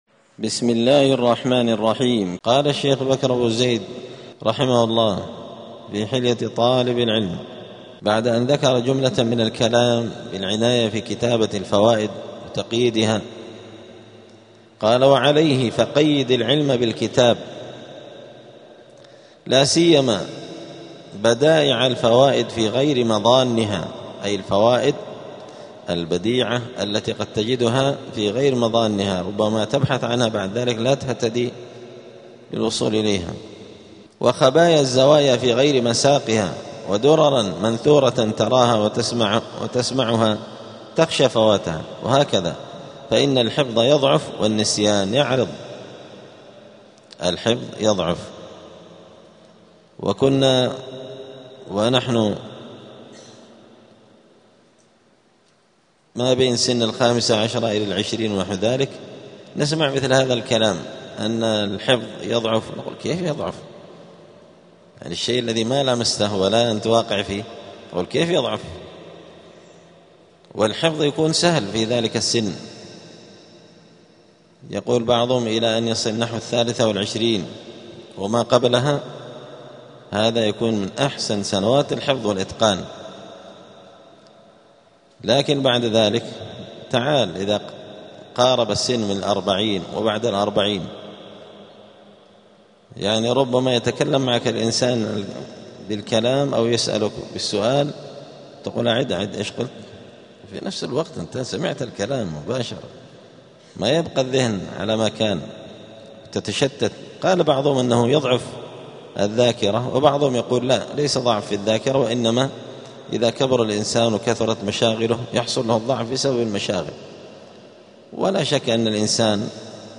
*الدرس الثامن والخمسون (58) فصل آداب الطالب في حياته العلمية {قيدوا العلم بالكتابة}.*
58الدرس-الثامن-والخمسون-من-كتاب-حلية-طالب-العلم.mp3